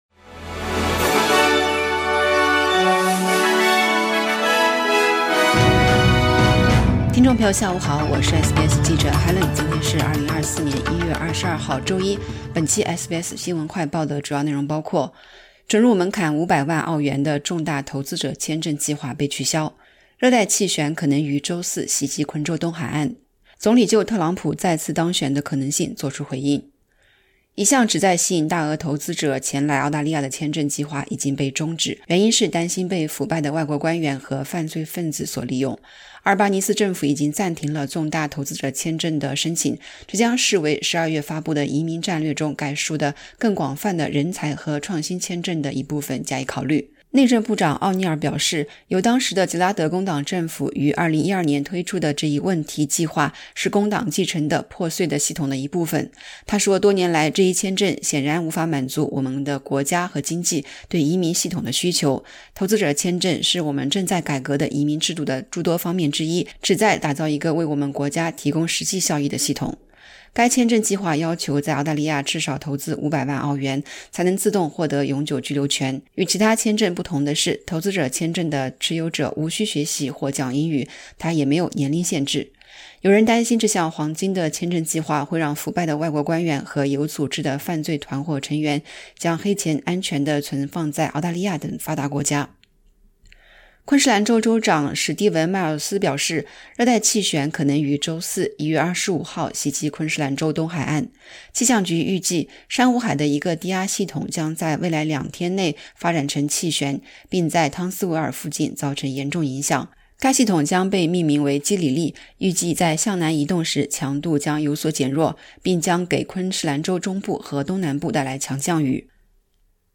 【SBS新闻快报】准入门槛500万澳元的重大投资者签证计划被取消